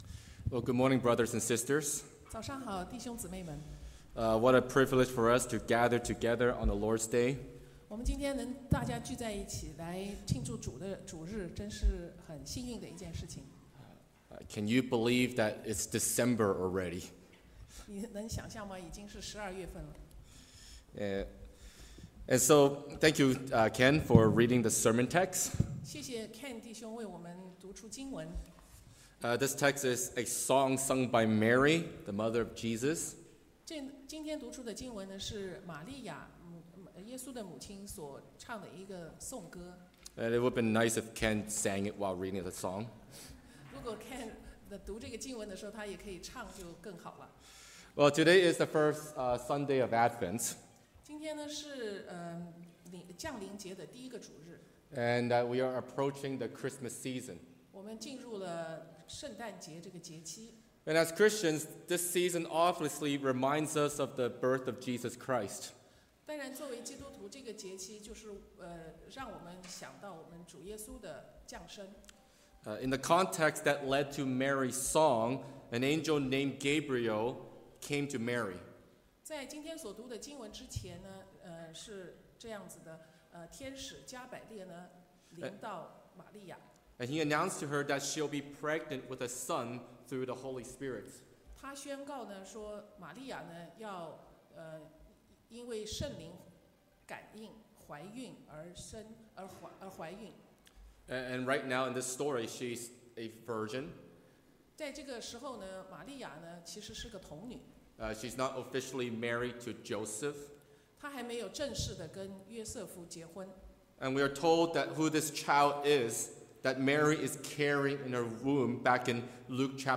Sermons | Oakridge Baptist Church